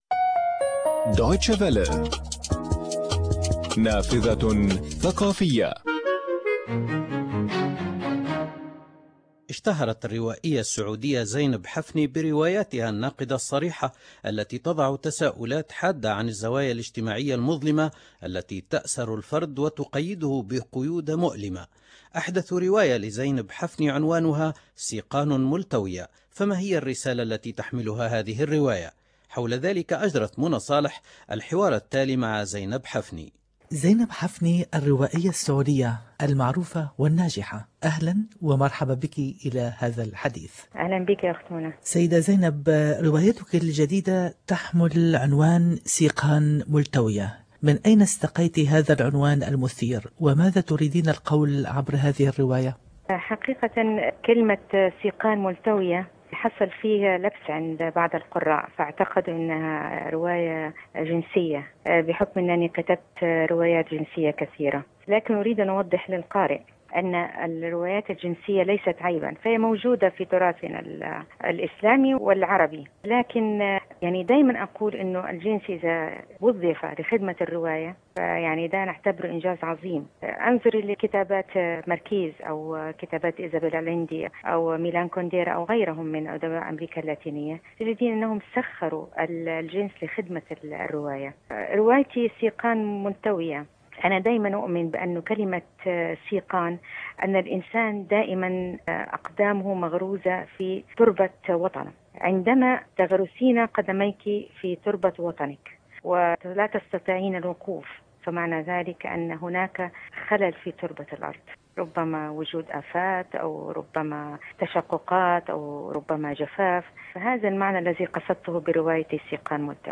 حوارات اذاعية 2008 | الكاتبة والاديبة زينب حفني